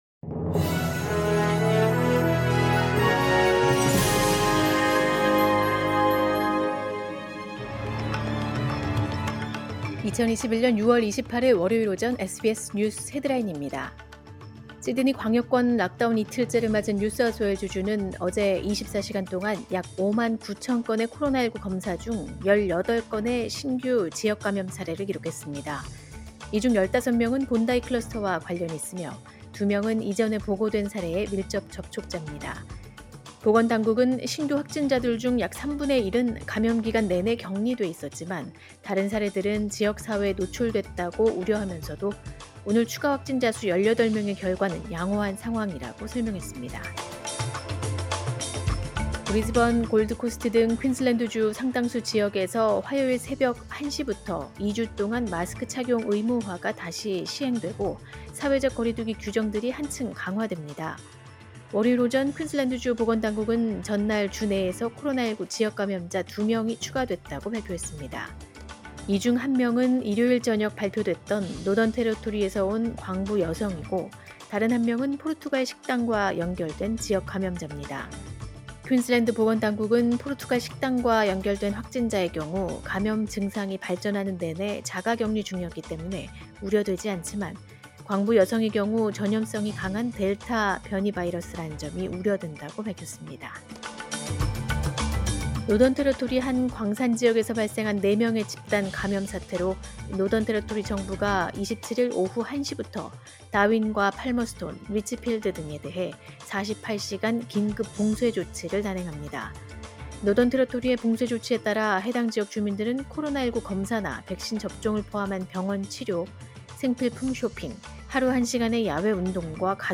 2021년 6월 28일 월요일 오전의 SBS 뉴스 헤드라인입니다.